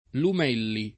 [ lum $ lli ]